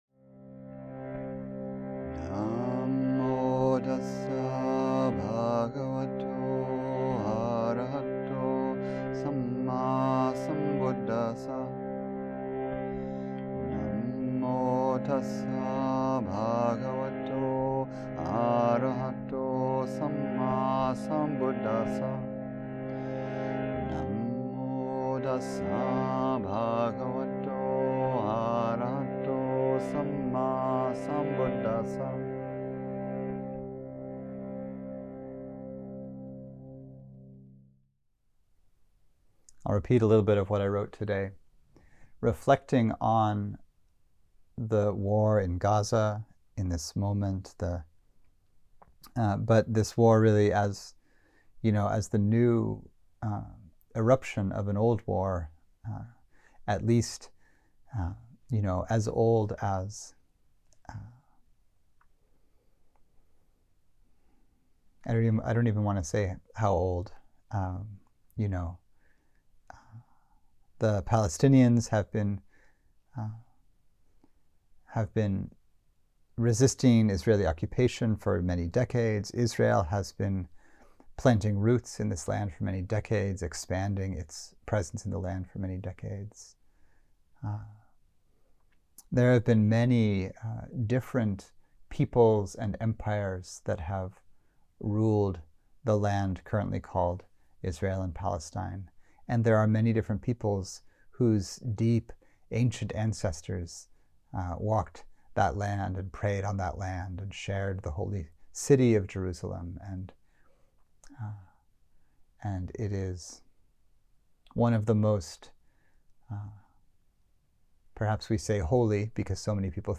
Recorded at Insight Meditation Satsang